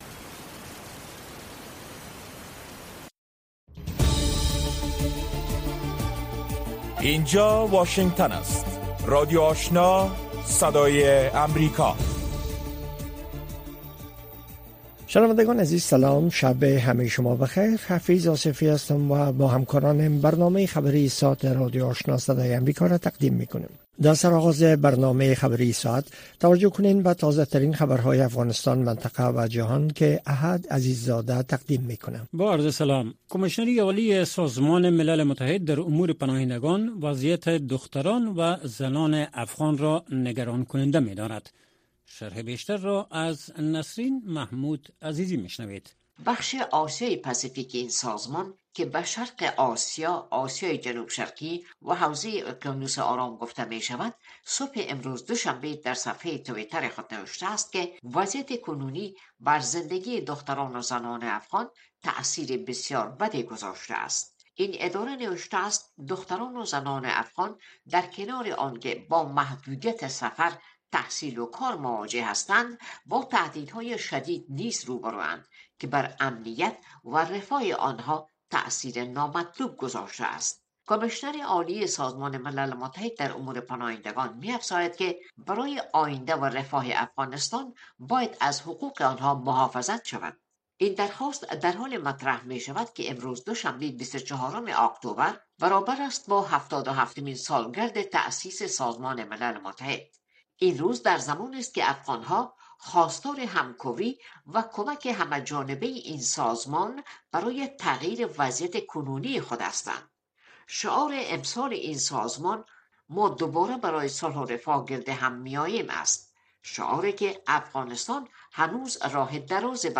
برنامۀ خبری شامگاهی